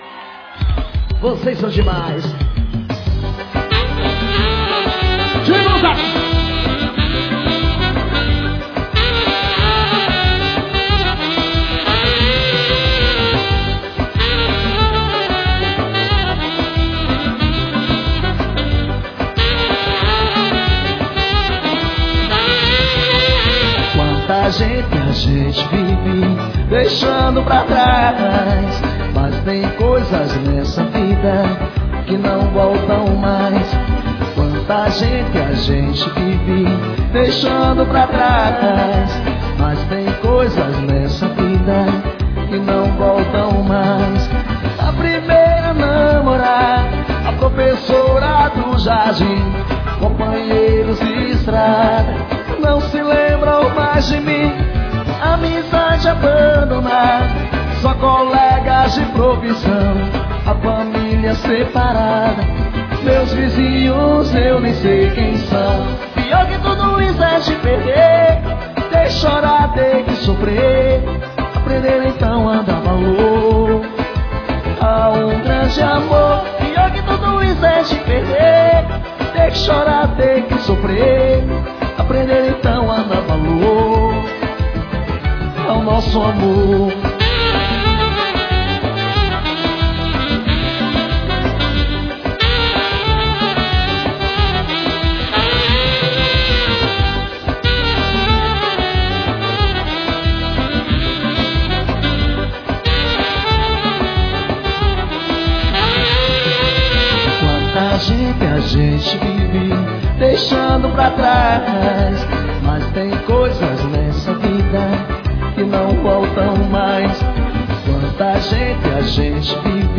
Arrocha.